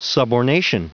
Prononciation du mot subornation en anglais (fichier audio)
Prononciation du mot : subornation